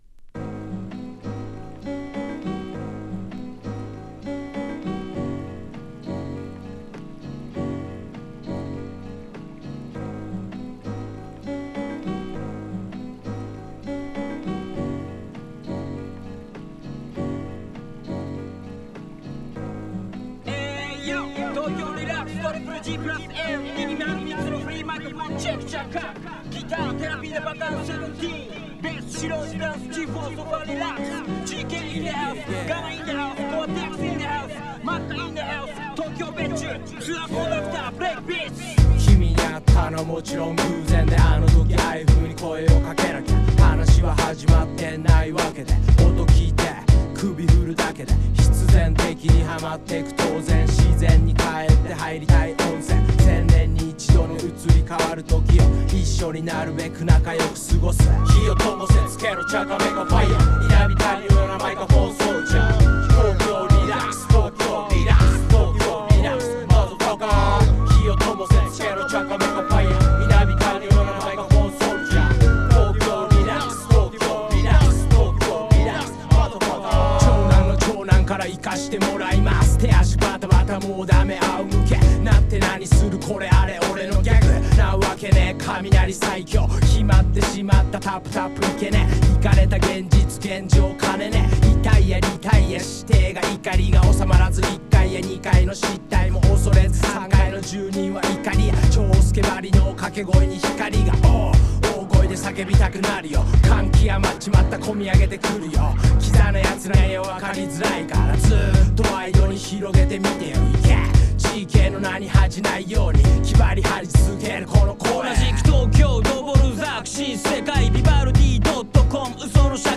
気持ちいいトラックもGood！